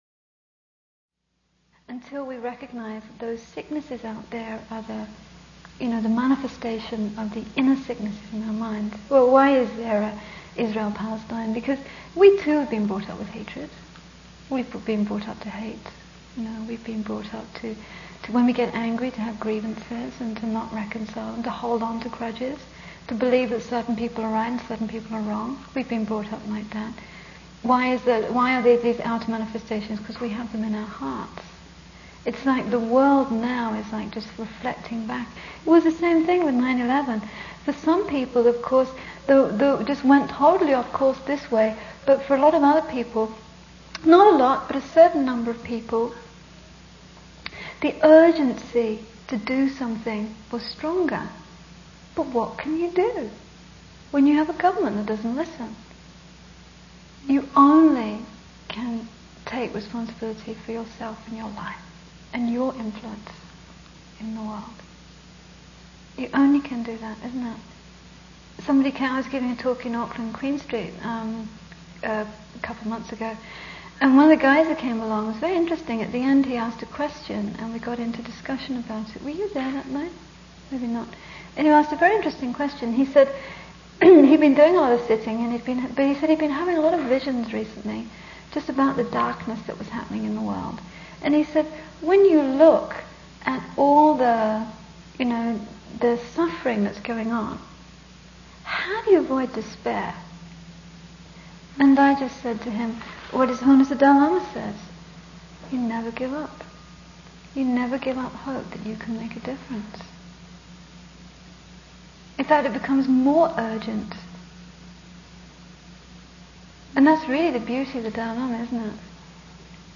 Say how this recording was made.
FPMT Audio Teachings Ultimate Healing Recorded at Mahamudra Centre, Coromandel, New Zealand January 2004 Low Res Version - 16k High Res Version - 32k Ultimate Healing - Pt 01 Ultimate Healing - Pt 02